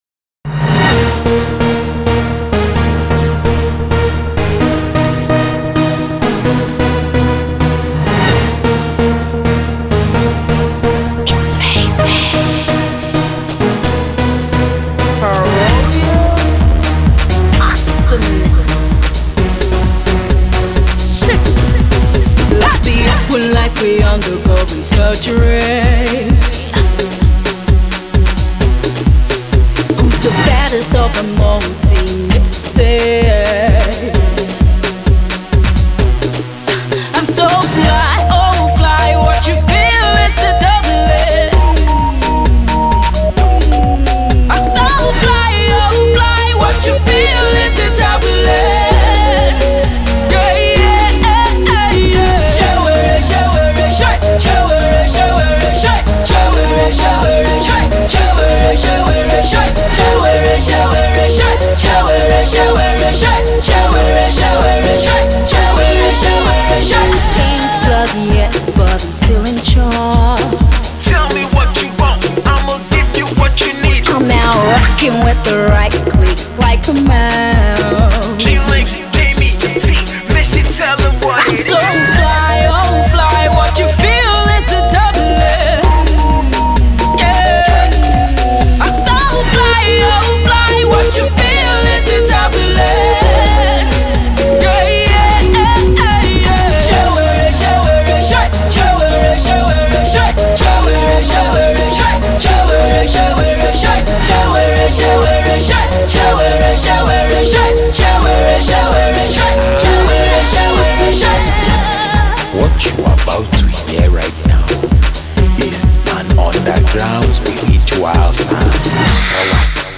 dance single